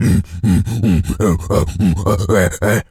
pgs/Assets/Audio/Animal_Impersonations/gorilla_chatter_07.wav at master
gorilla_chatter_07.wav